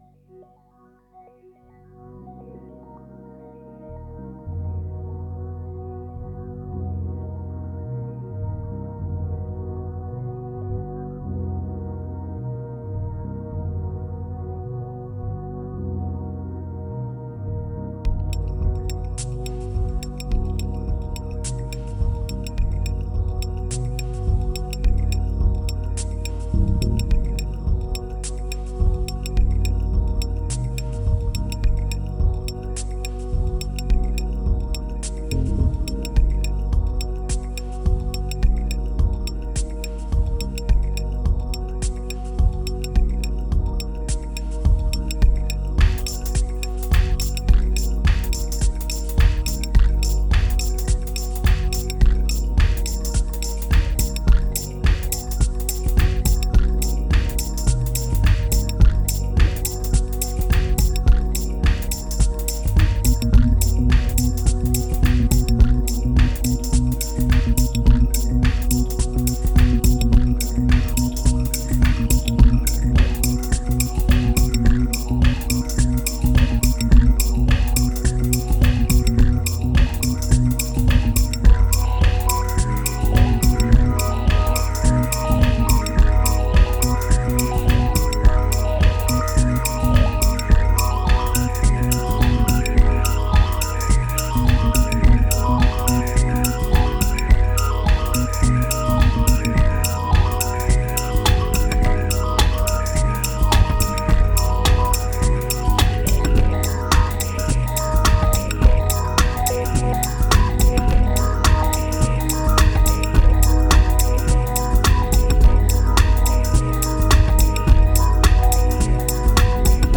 1986📈 - 20%🤔 - 106BPM🔊 - 2014-05-19📅 - -195🌟